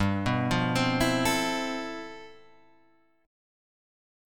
G13 chord